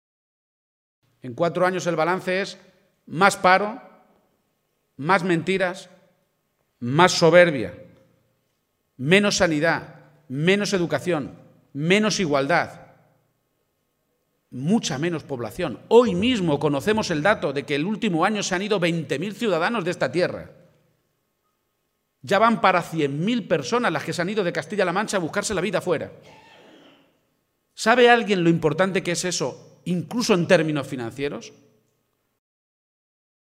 El secretario general regional y candidato del PSOE a la Presidencia de Castilla-La Mancha, Emiliano García-Page, ha aprovechado hoy un acto público en la localidad toledana de Sonseca para hacer balance de estos cuatro años de legislatura de Cospedal y ha sido muy rotundo al señalar que «hay más paro, más deuda, más soberbia, más mentira y menos sanidad, menos educación y menos población.
Audio García-Page en Sonseca 1